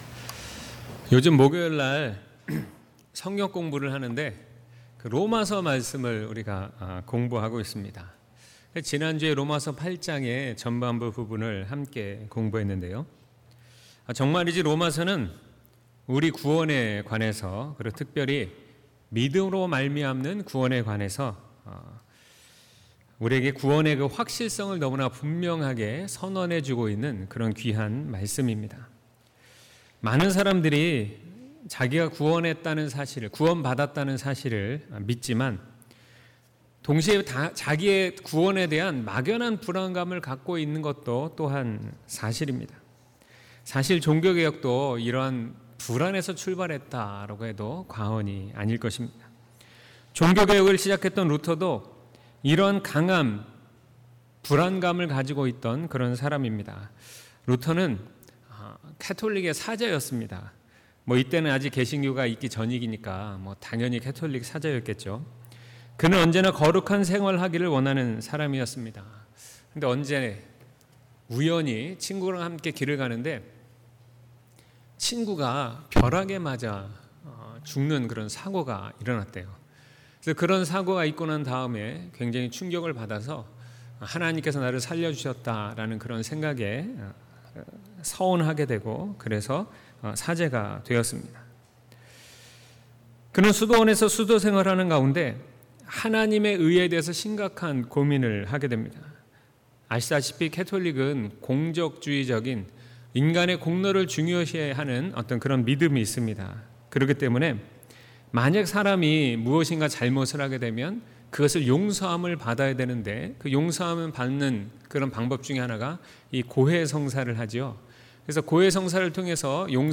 2019년 6월 23일 주일설교/ 하나님의 의/ 롬1:16-17